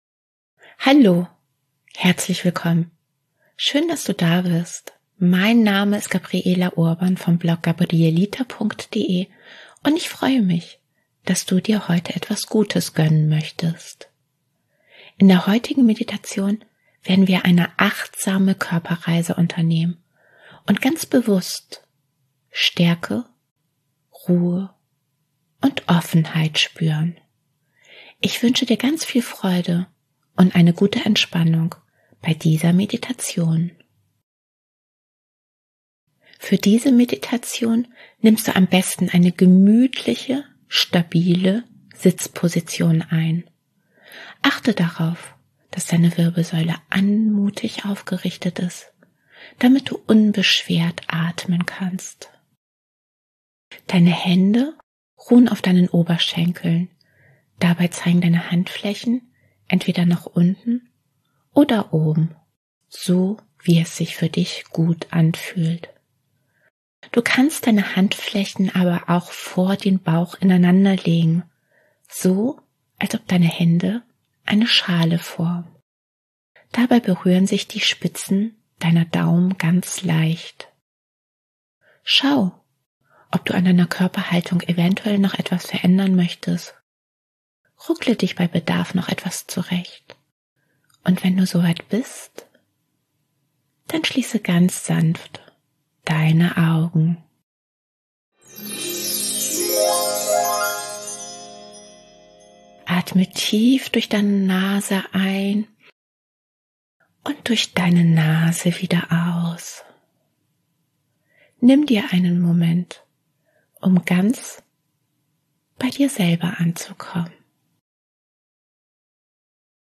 Die heutige Meditation ist eine achtsame Körperreise, denn wir werden unsere Aufmerksamkeit bewusst in den Körper lenken und eine kleine Reise durch den Körper unternehmen, in der wir vor allem Stärke, Ruhe und Offenheit spüren werden.